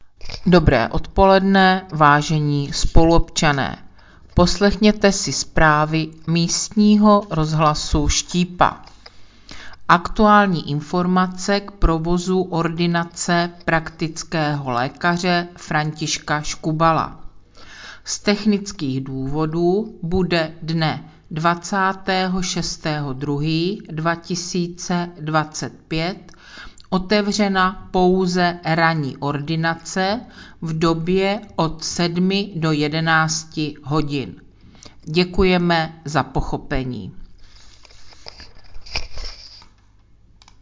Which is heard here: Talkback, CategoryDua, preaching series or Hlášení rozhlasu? Hlášení rozhlasu